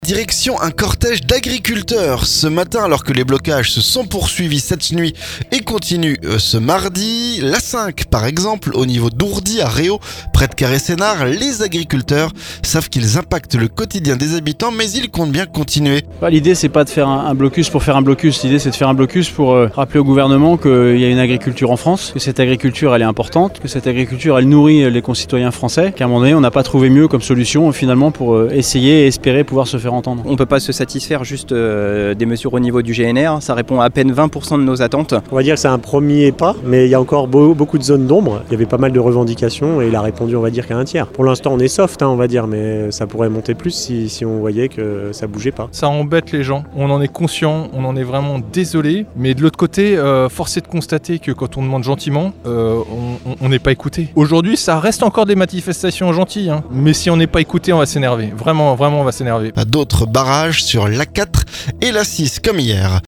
AGRICULTEURS - Reportage sur le blocage de l'A5, à Réau